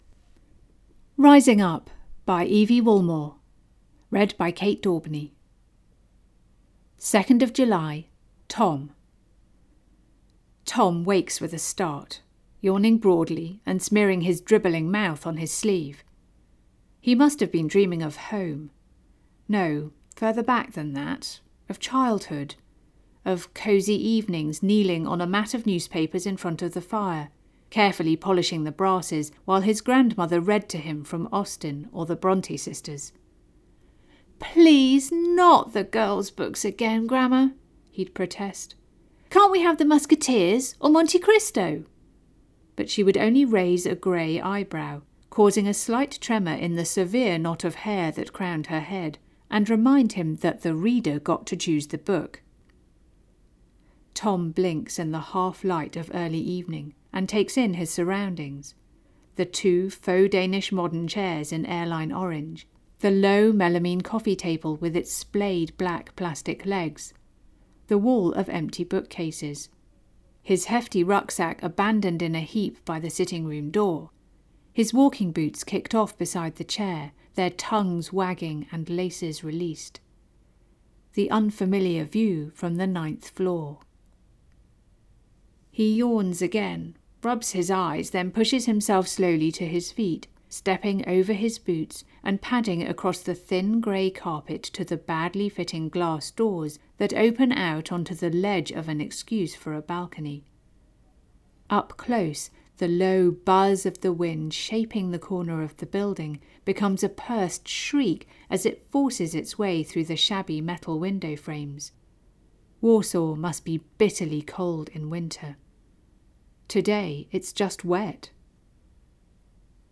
audiobook sample
rising-up-by-evie-woolmore-audiobook-sample.mp3